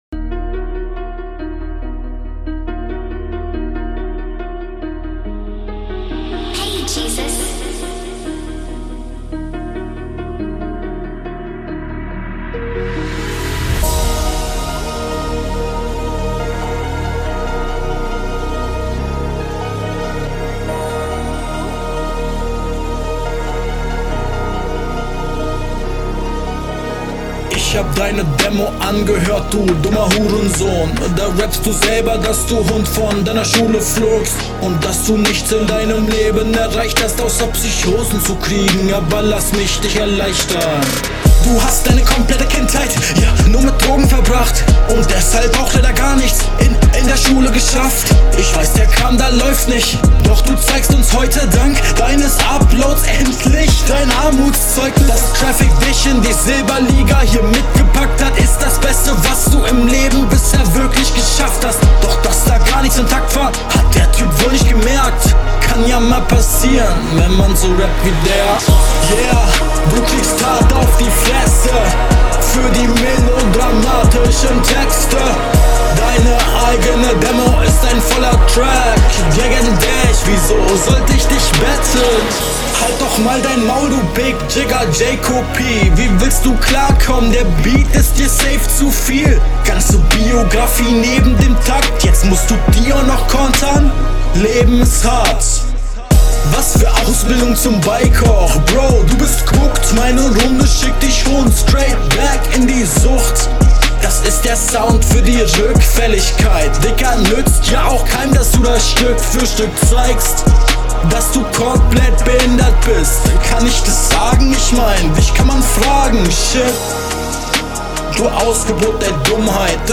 Sag mal.. aber stabile Runde, wirkt zerstörerisch.
Schön epische Stimmung, gut darauf geflowt, stetiger Gegnerbezug, die Reime stechen hervor, weil sie sehr …